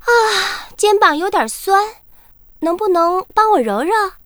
文件 文件历史 文件用途 全域文件用途 Daphne_tk_04.ogg （Ogg Vorbis声音文件，长度0.0秒，0 bps，文件大小：56 KB） 源地址:游戏语音 文件历史 点击某个日期/时间查看对应时刻的文件。